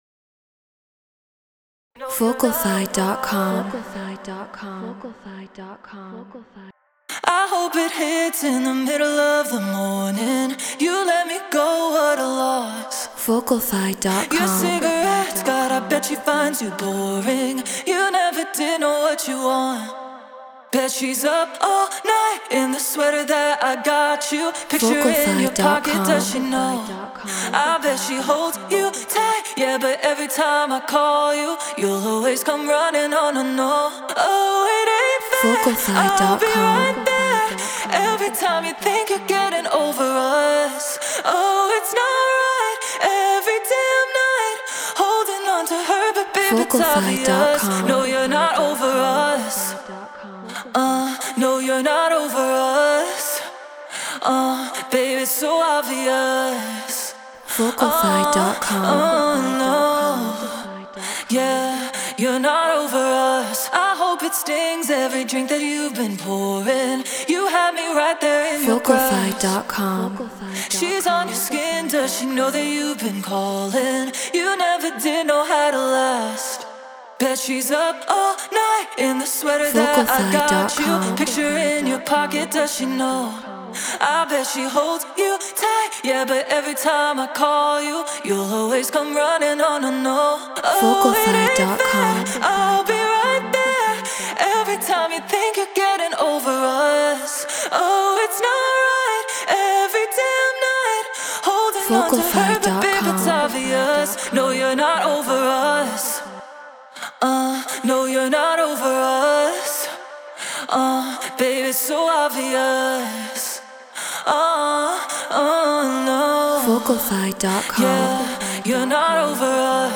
UKG 136 BPM Amin
Human-Made
Flea 47 Apogee Symphony Mark ii Logic Pro Treated Room